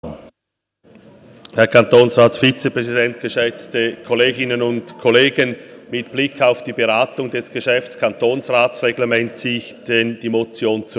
18.9.2019Wortmeldung
Session des Kantonsrates vom 16. bis 18. September 2019